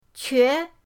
que2.mp3